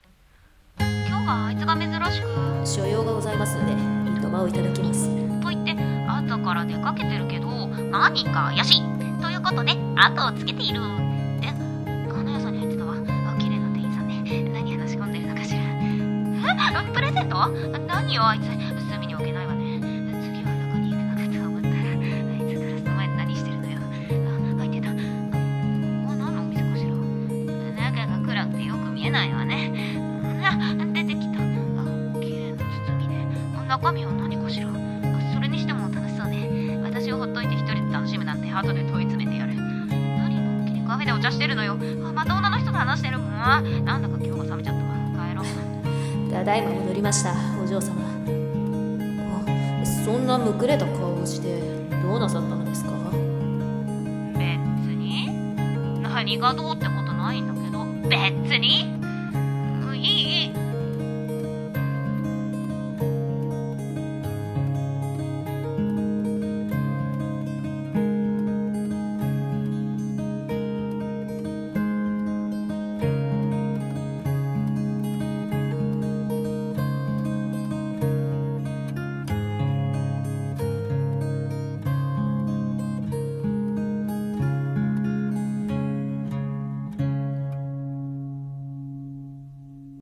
【一人二役】お嬢様と『クリスマス①』